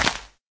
grass3.ogg